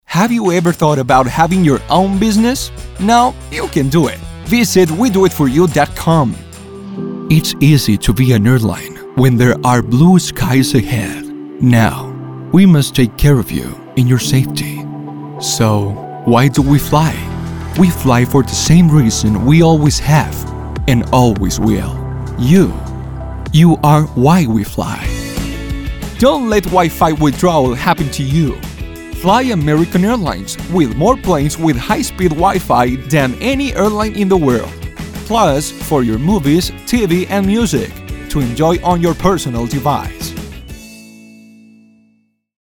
Very wide voice range.
English Voice Over Demo
Español Neutro Latino - Spanish Latin America - English Latino